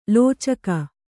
♪ lōcaka